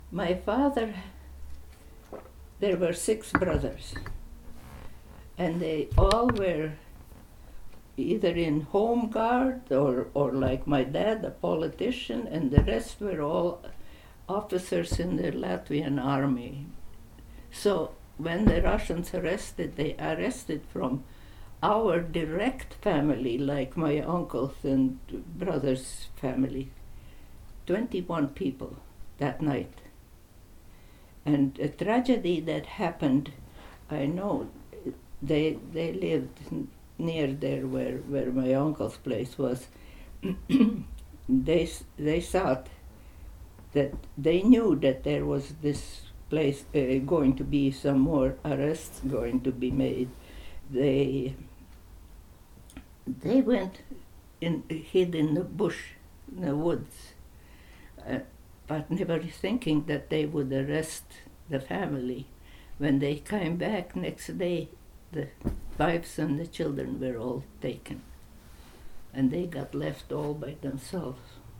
Interviewer (ivr)